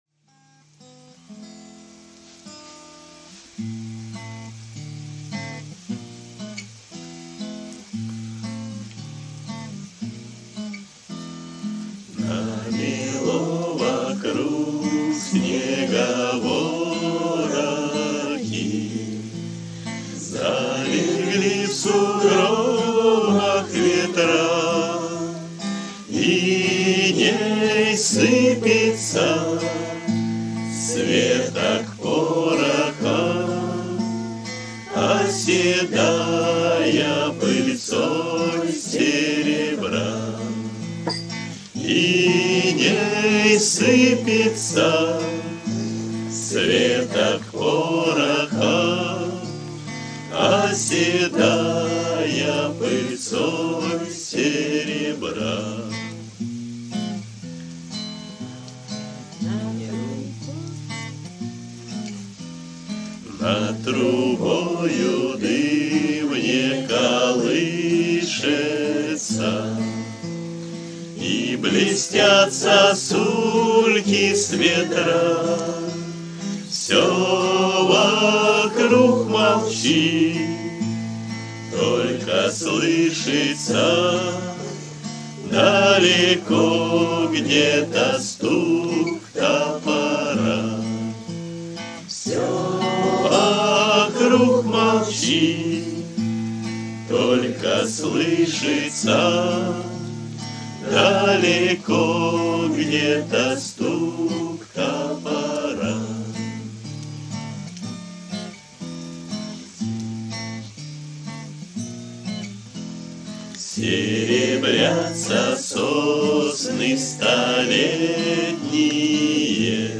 кавер-версия на мотив песни